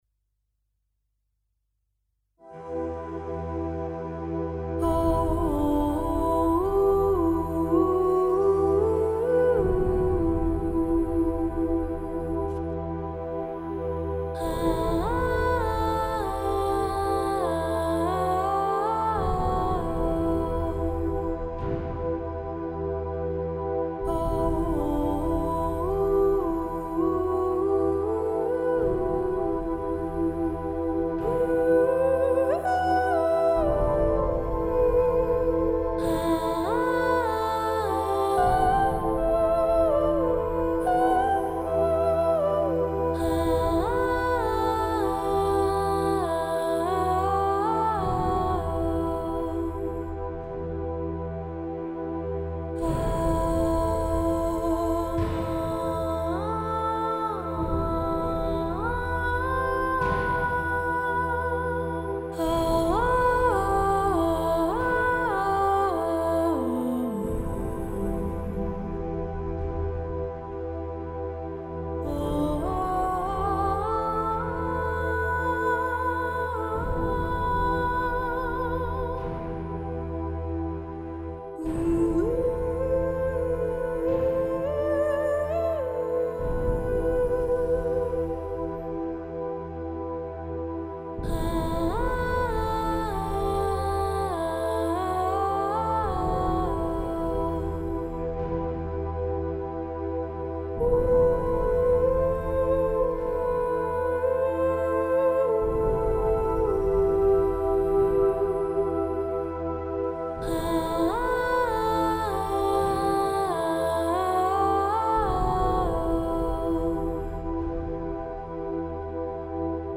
A few days back I got a new vocal sample package, and that impressed me - so I wrote something using some of the chopped up stock stuff with some pads. It's for no client, has no purpose but experience for me on the new VST.
I doubt it will get finished - but I wrote it just to experiment, and despite being slow and sad, it cheered me up.